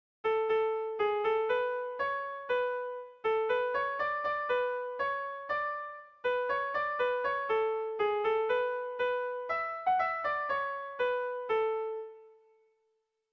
Kontakizunezkoa
Santa Grazi < Basabürüa < Zuberoa < Euskal Herria
Kopla handiaren moldekoa
ABD